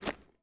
box_put.ogg